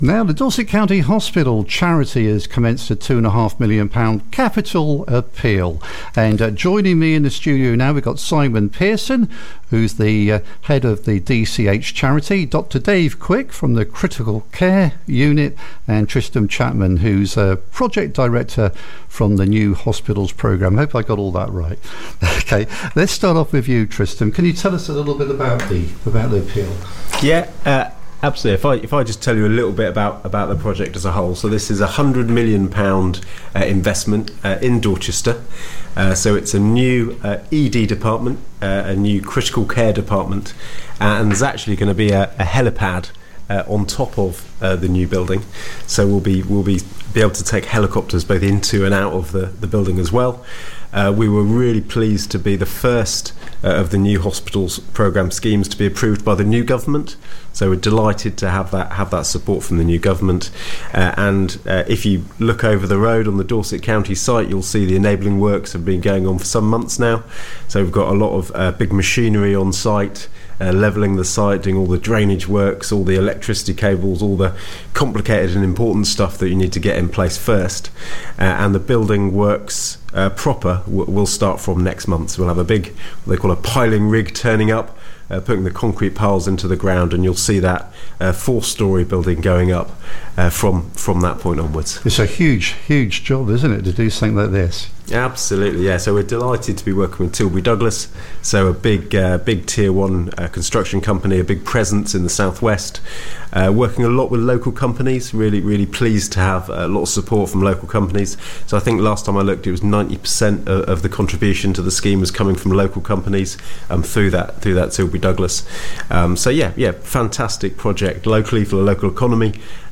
in the KeeP 106 studio